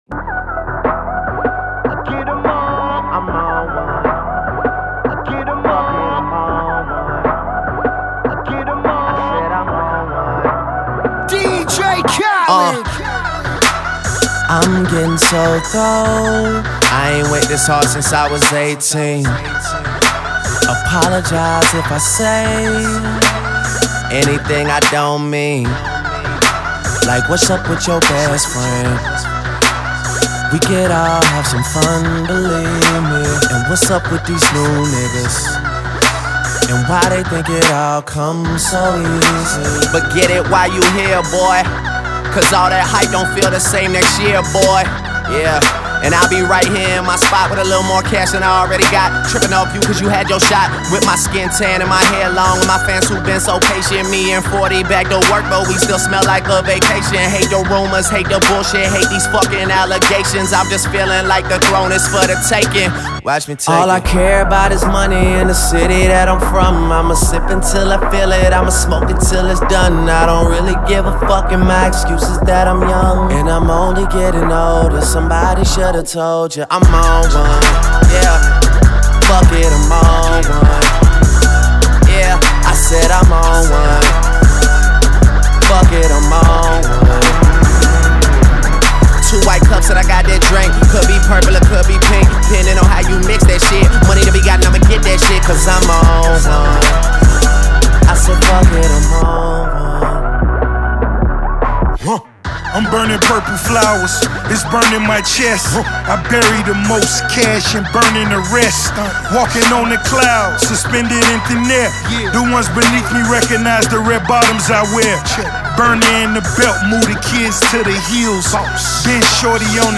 posse cuts
rapper-singer style